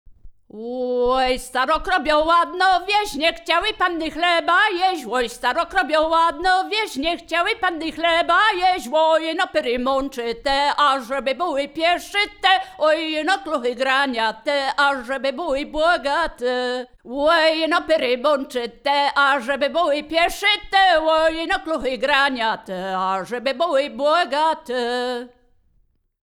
Wielkopolska
liryczne miłosne żartobliwe przyśpiewki